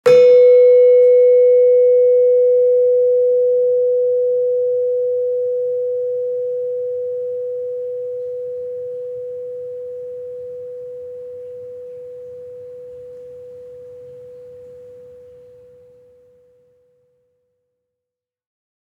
Gamelan Sound Bank